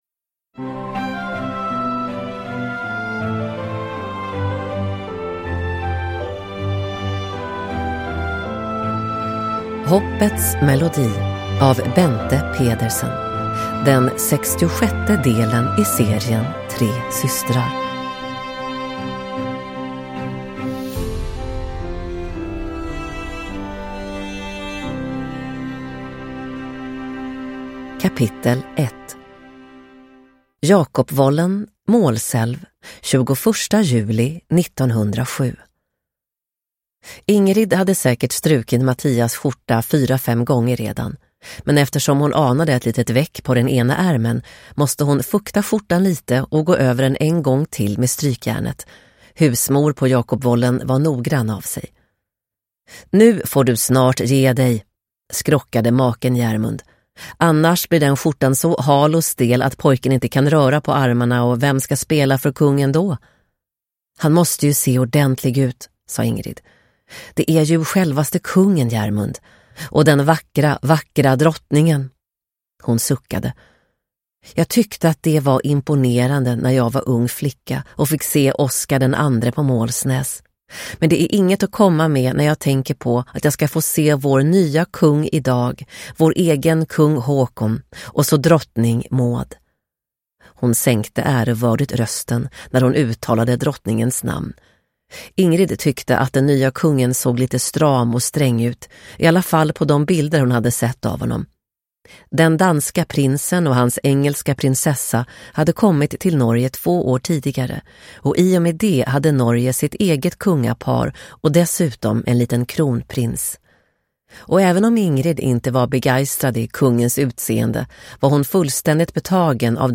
Hoppets melodi – Ljudbok – Laddas ner